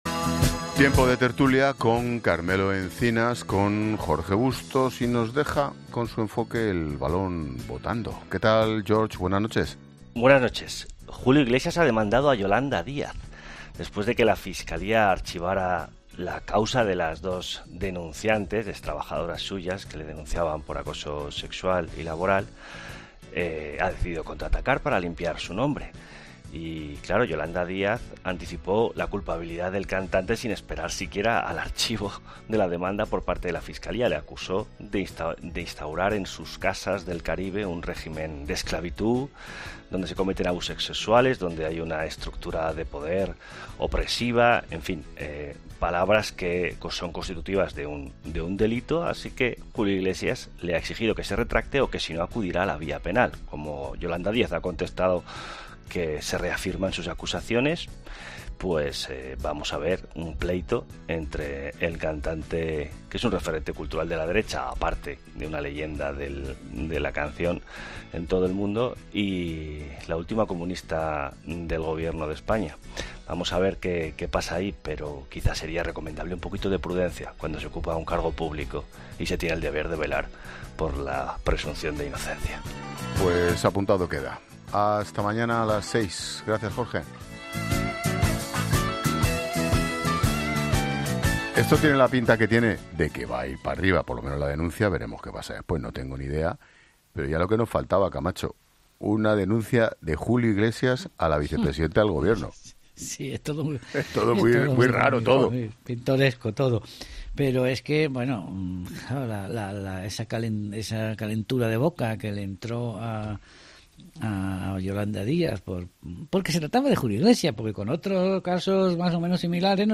Este asunto, analizado en el tiempo de tertulia de 'La Linterna' de COPE, marca un nuevo episodio en la confrontación entre el mundo de la política y figuras de relevancia pública.